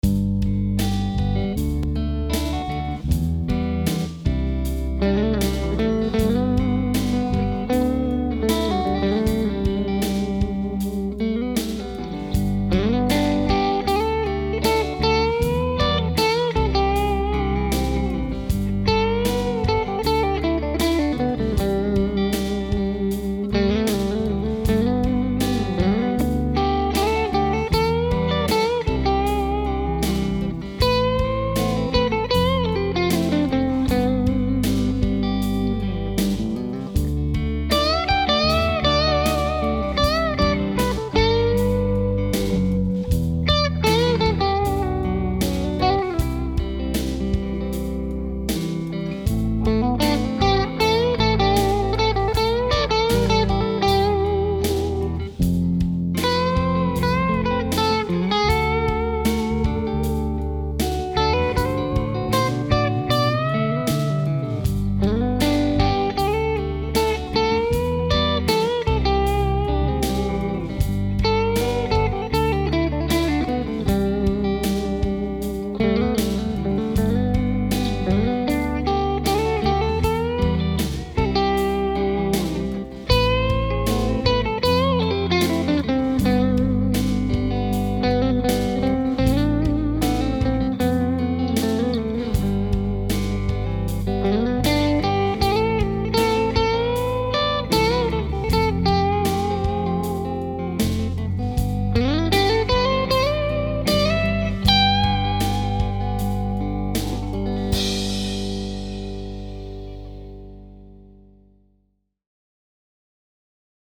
First Studio Test: 1959 Les Paul Replica
Amp: Aracom VRX22
Rhythm Part (left): Channel 1, Volume at halfway, Tone at 2pm; Guitar in neck position, volume at 5
Lead (Right): Channel 1, Volume Cranked, Tone at 2pm; Guitar in middle position, neck volume 5, bridge volume 8
Close miked with a Senheiser e609. Amp was attenuated with an Aracom PRX150-Pro.
With this particular song, I wanted to capture how great it sounds clean, and just slightly overdriven.
lp59clean.mp3